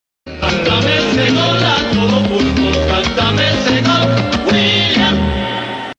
sports Meme Sound Effect